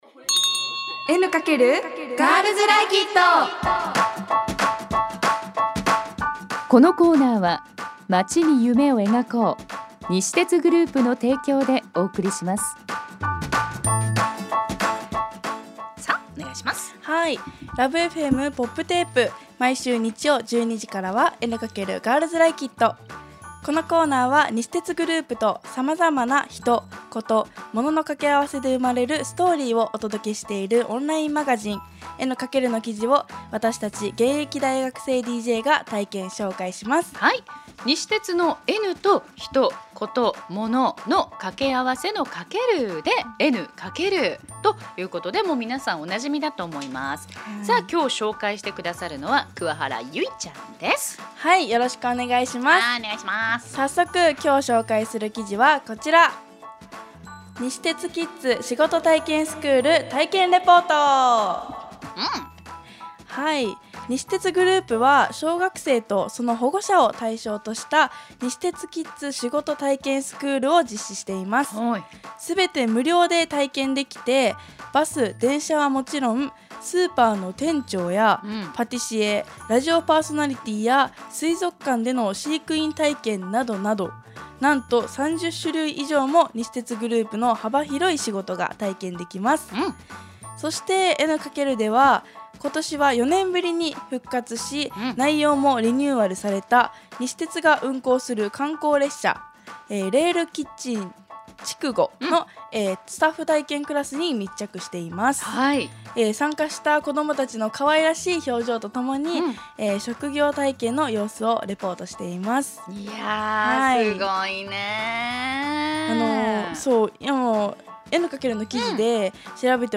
女子大生DJが「N× エヌカケル」から気になる話題をピックアップ！ 第70回目は男性も育休取得の時代。夫婦で交互に子育てに取り組む西鉄のダイバーシティ！の記事を紹介しました！